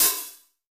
BOPHH 03.wav